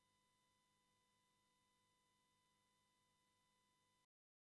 Rock'n'roll fra gamle dage til seniorer.
• Coverband